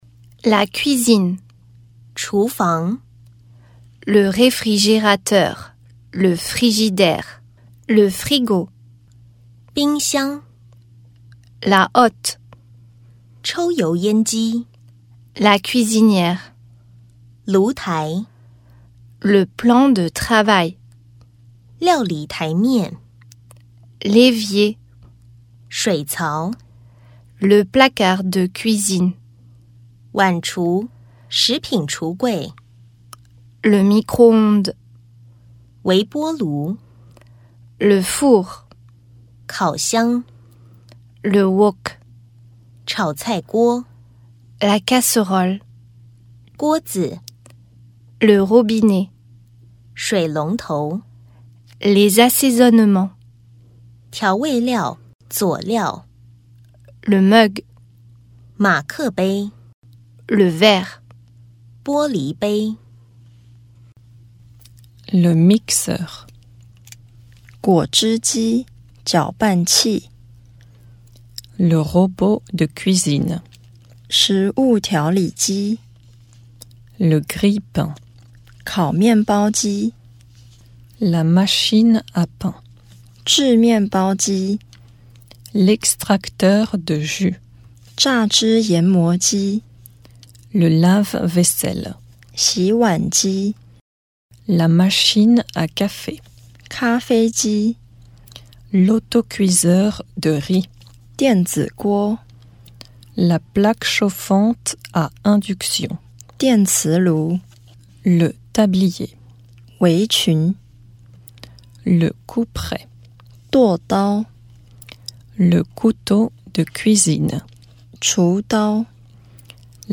純正法國人發音，你可以透過聽力加深對單字的熟悉度，並加強聽力的基礎，更同時學習到正確優美的法語。